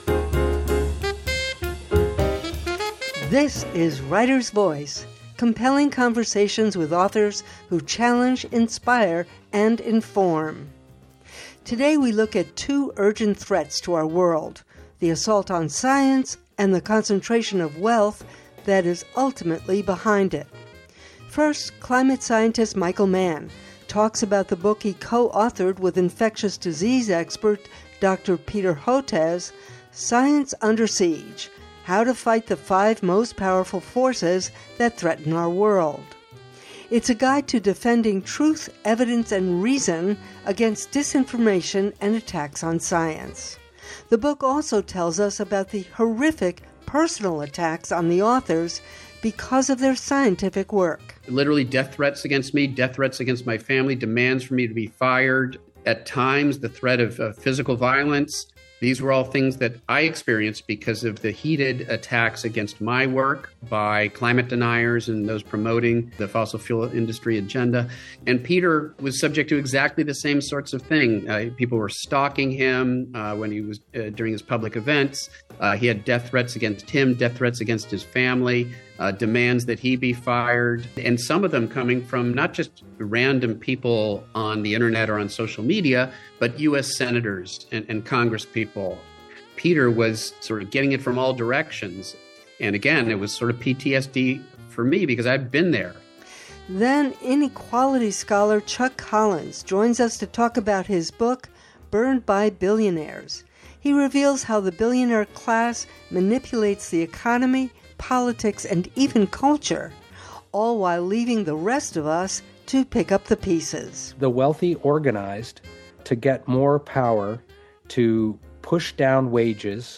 Writer’s Voice: compelling conversations with authors who challenge, inspire, and inform. Today, we look at two urgent threats to our world: the assault on science and the concentration of wealth.